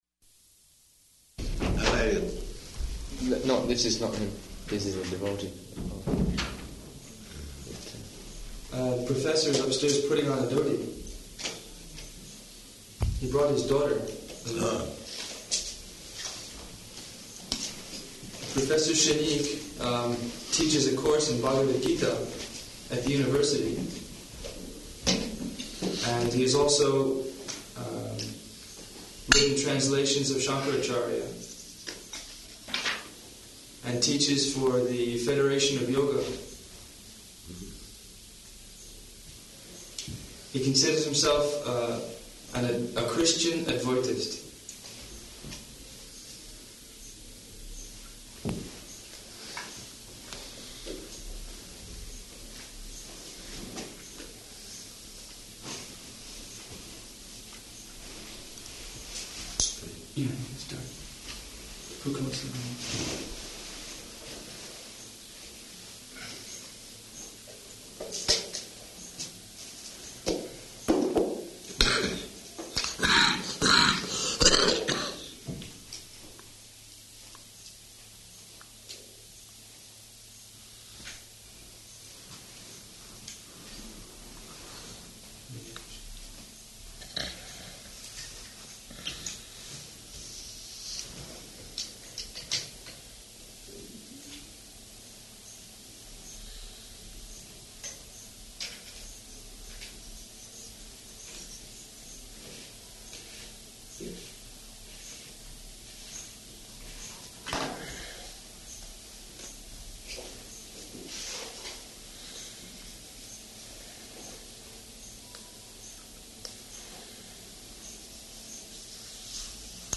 -- Type: Conversation Dated: August 5th 1976 Location: New Māyāpur Audio file